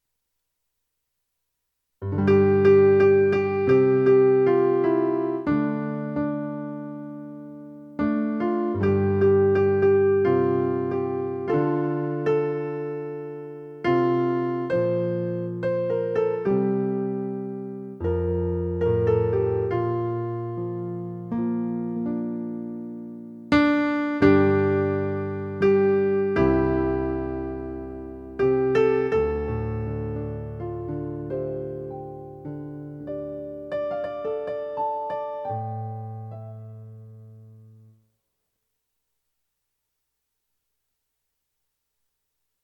Create In Me - Cantor - Verse 4